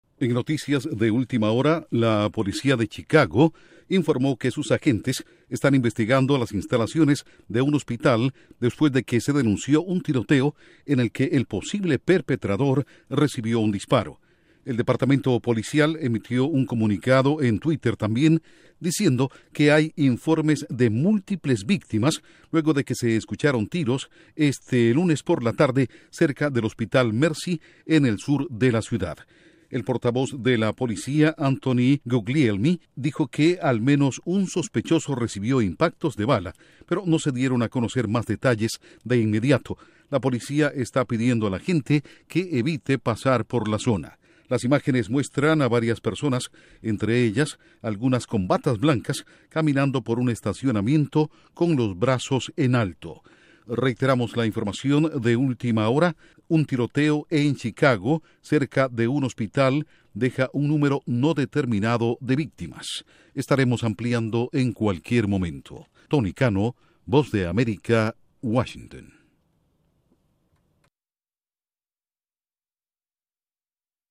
Última hora